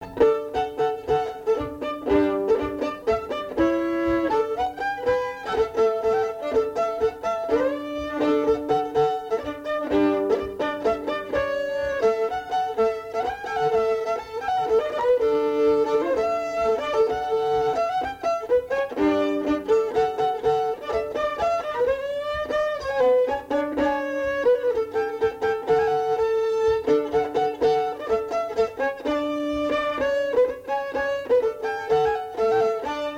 danse : polka
Pièce musicale inédite